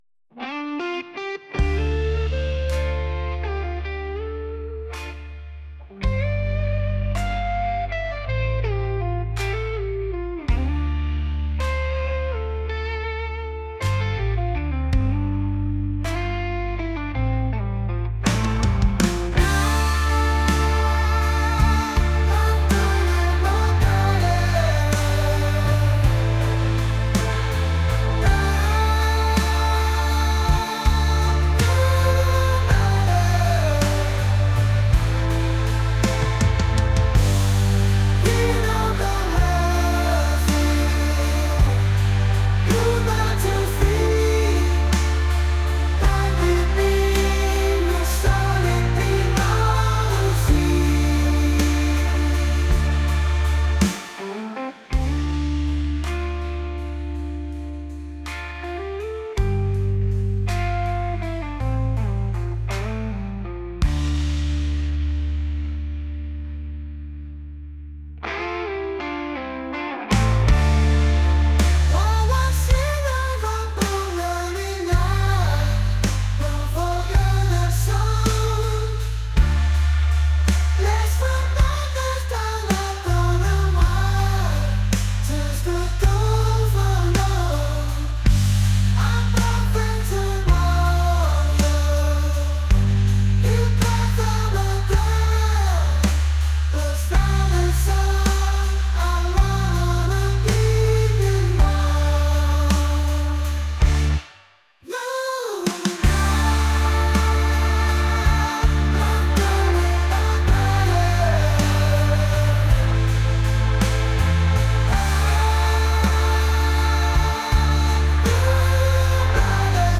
rock | indie | retro